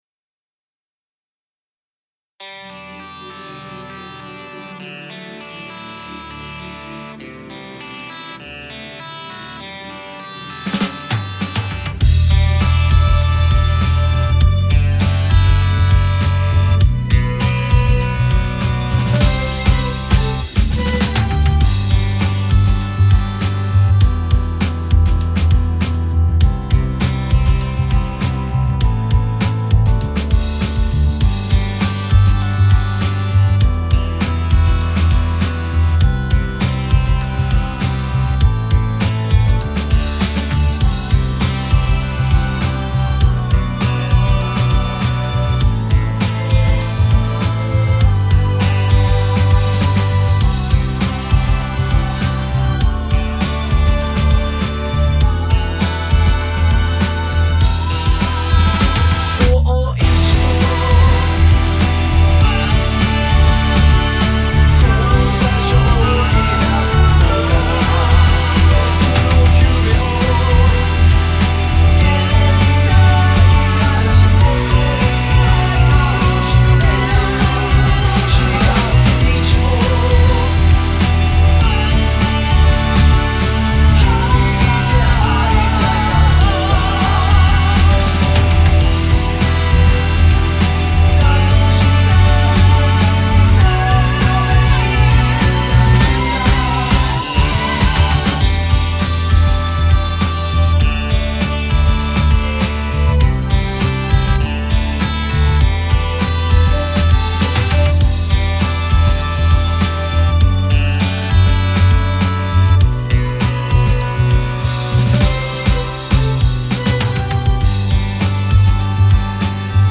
インスト版（カラオケ）　ガイドメロなし